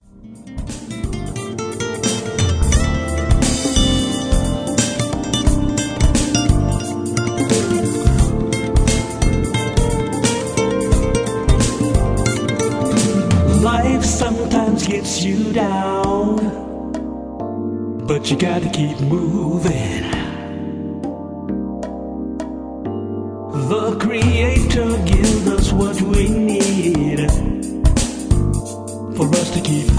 Smooth jazz vocal song with inspirational lyrics